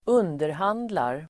Ladda ner uttalet
underhandla verb, negotiate Grammatikkommentar: A & med B Uttal: [²'un:derhan:dlar] Böjningar: underhandlade, underhandlat, underhandla, underhandlar Definition: förhandla, överlägga Avledningar: underhandling (negotiation)